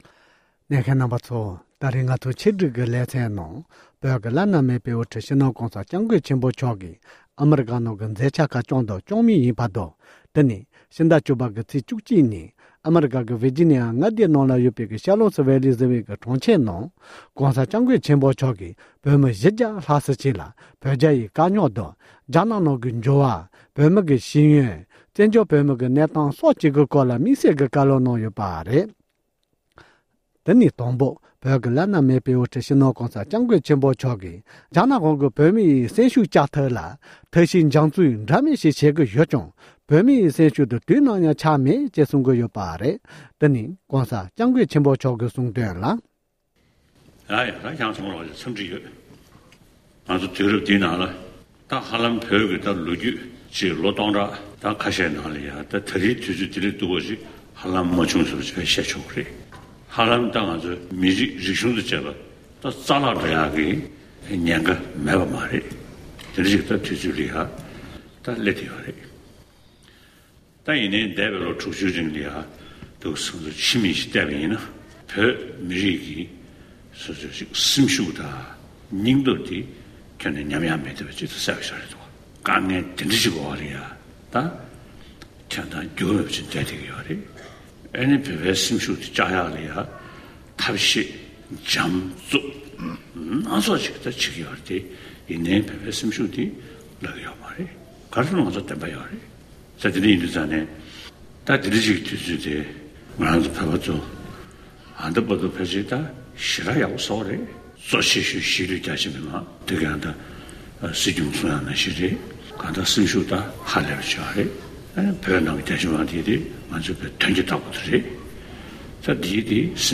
༸གོང་ས་མཆོག་གི་དམིགས་བསལ་བཀའ་སློབ།
ཝར་ཇི་ནི་ཡའི་ཁུལ་གྱི་བོད་མི་ཚོར་དམིགས་བསལ་མཇལ་ཁ་དང་བཀའ་སློབ་གནང་བ།